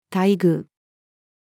待遇-female.mp3